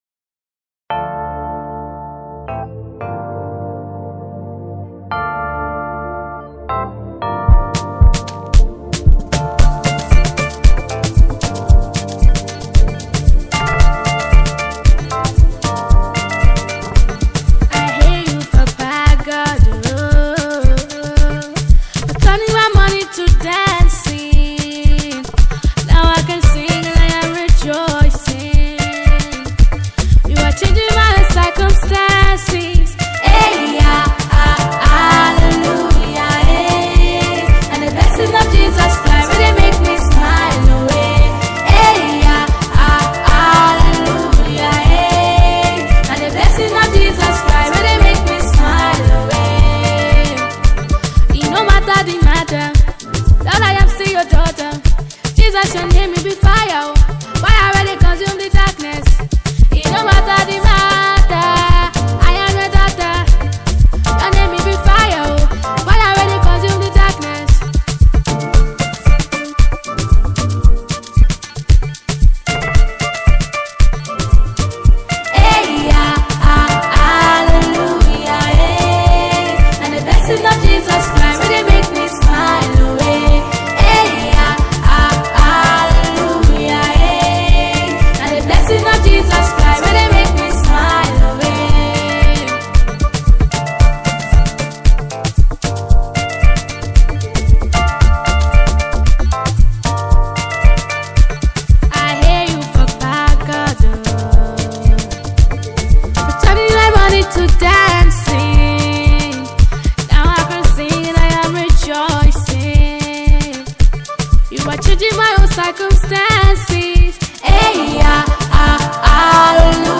Nigeria Gospel Music
a powerful worship song